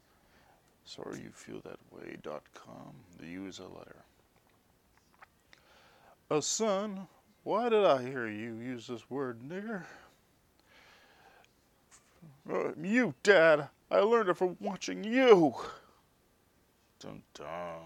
Satirical Skits In Audio, No Holds Barred.